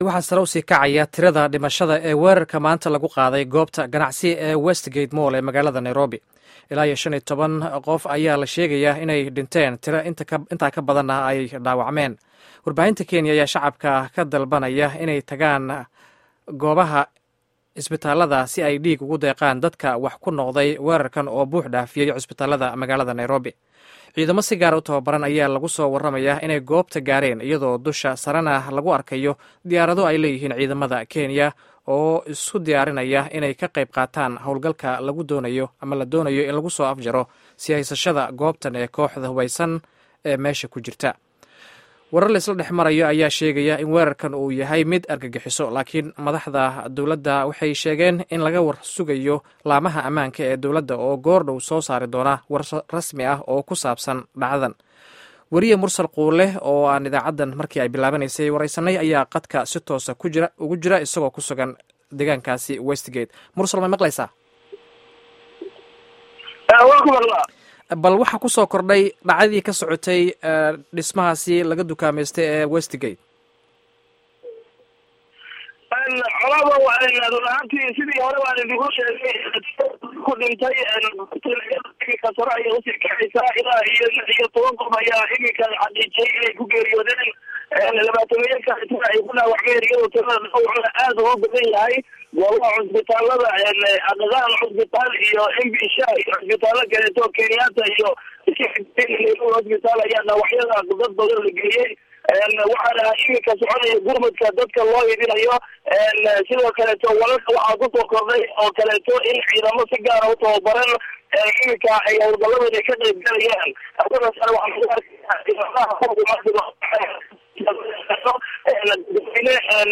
Dhageyso Wareysi Weerarka Nairobi